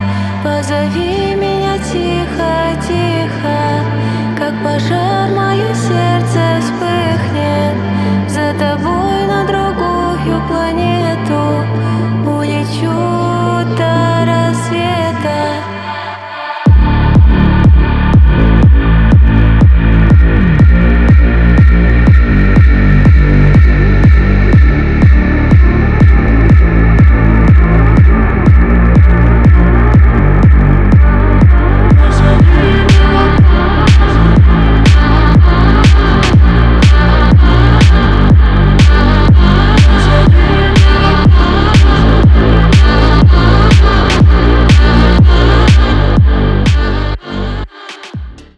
• Качество: 320, Stereo
deep house
красивый женский голос
клубная музыка